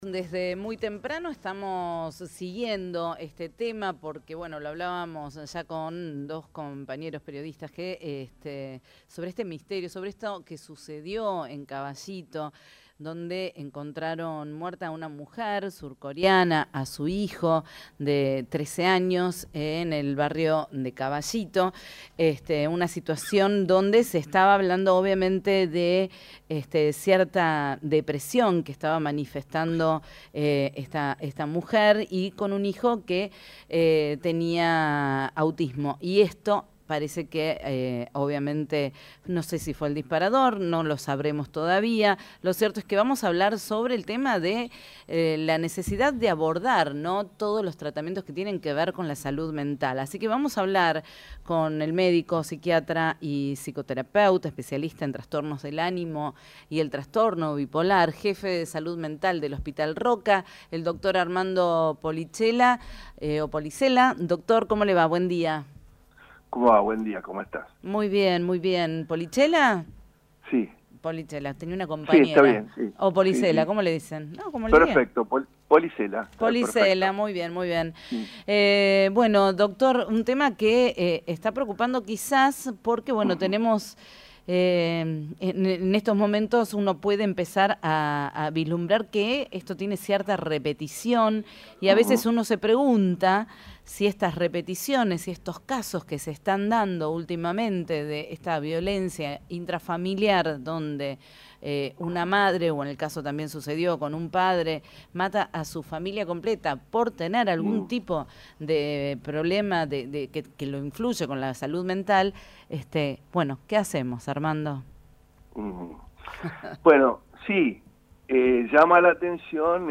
Entrevista
psiquiatra.mp3